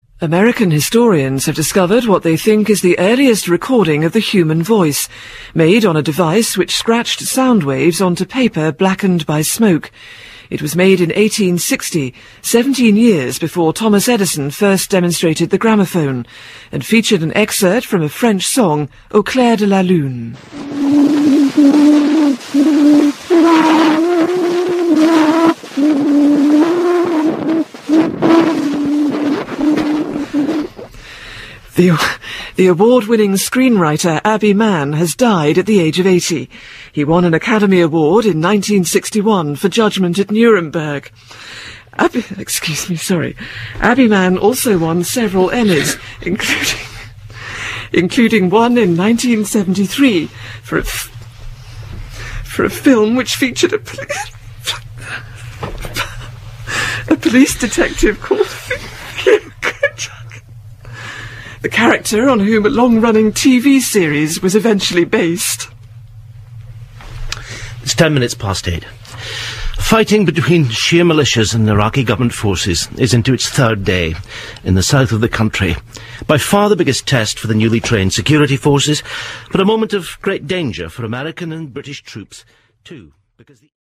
public service announcements
Charlotte Green cracks up on air, 2008, MP3, 1.3MB